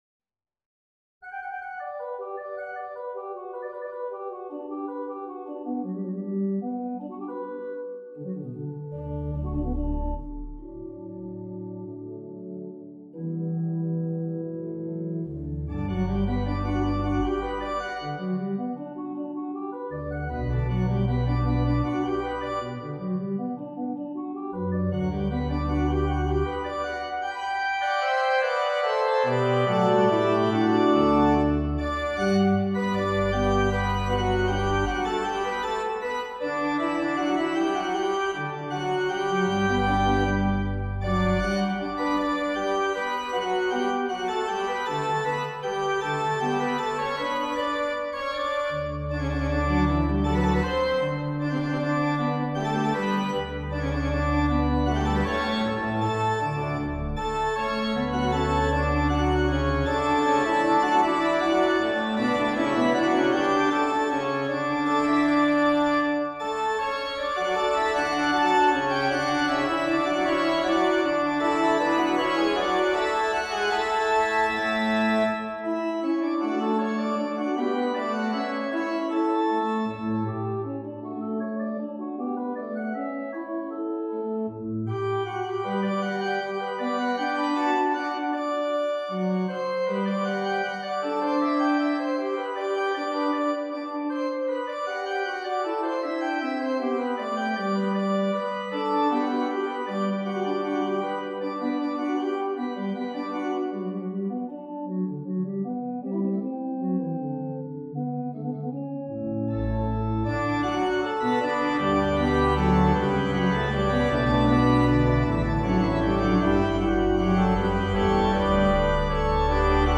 for organ
Opening arpeggios on the tonic major seven chord introduce the work, the fugue subject withheld until the pickup of measure 14. The registration is indicated such that a terraced set of dynamics are immediately evident. Marked giocoso, the fugue should be spirited and yet flowing.